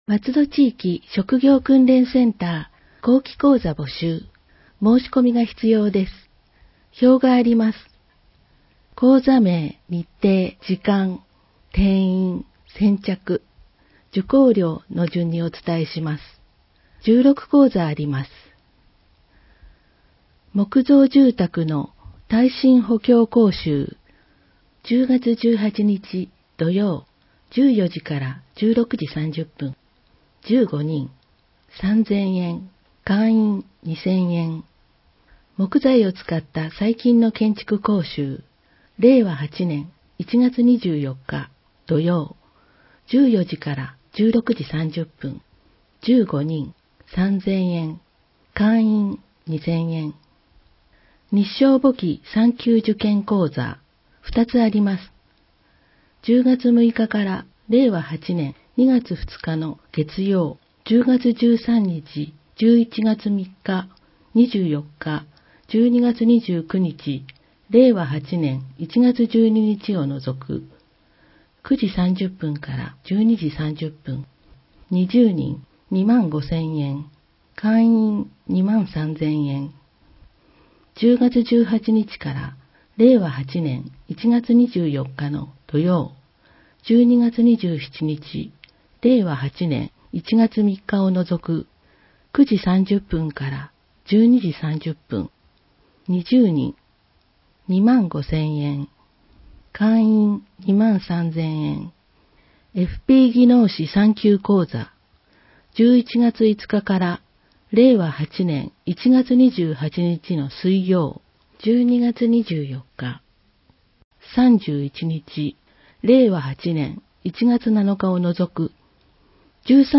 松戸朗読奉仕会のご協力で、広報まつどの音声版を公開しています。